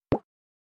object_remove.mp3